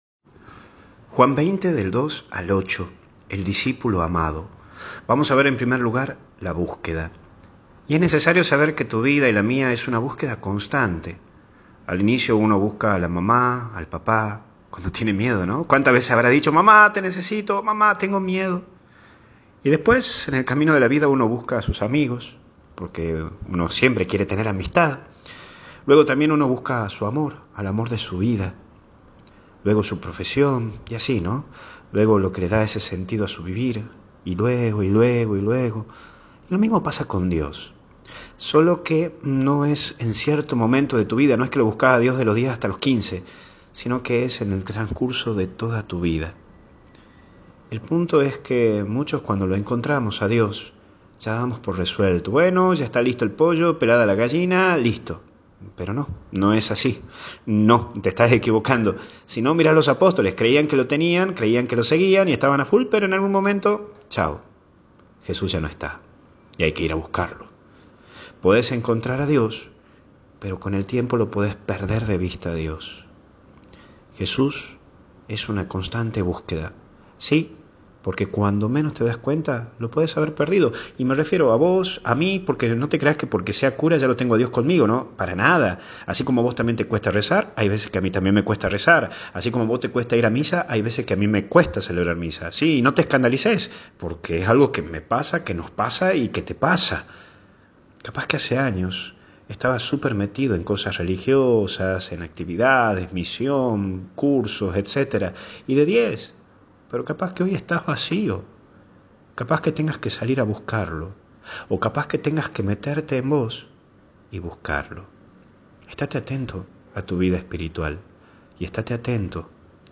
Daily Meditation